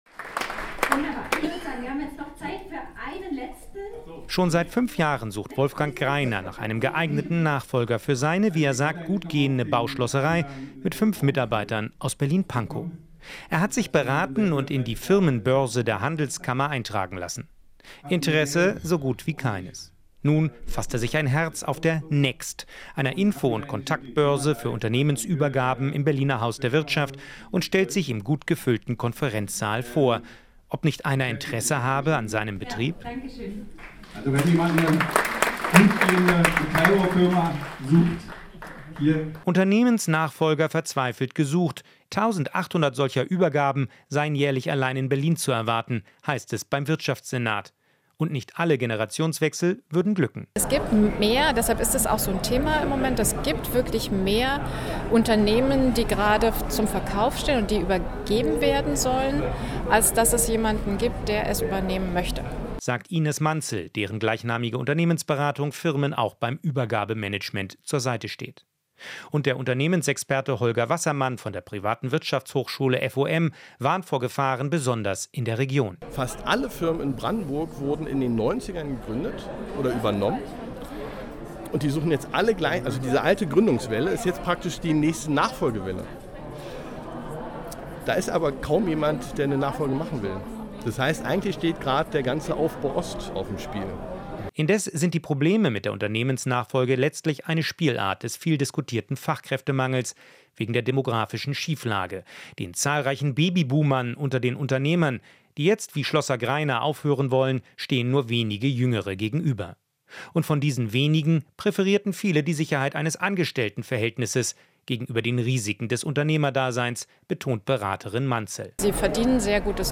Inforadio Nachrichten, 27.04.2023, 13:20 Uhr - 27.04.2023